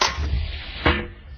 mortar_load.ogg